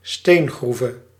Ääntäminen
IPA: /ka.ʁjɛʁ/